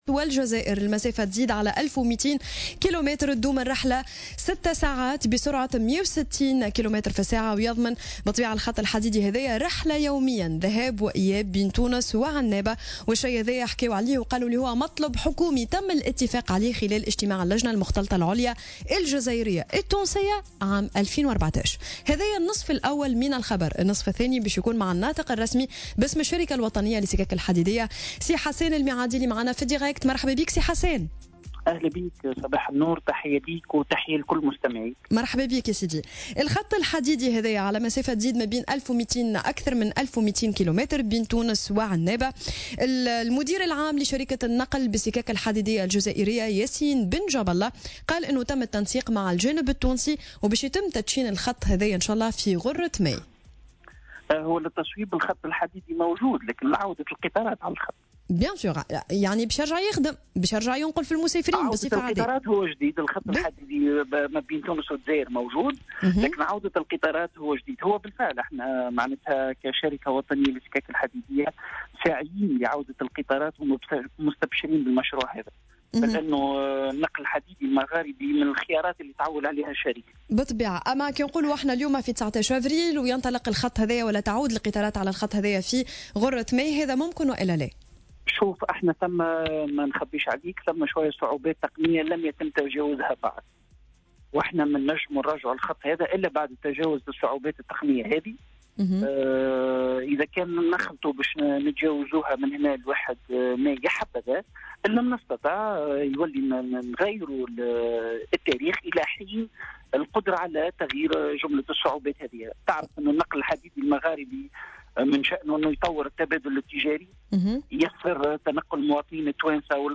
في مداخلة له اليوم في برنامج "صباح الورد" على "الجوهرة أف أم"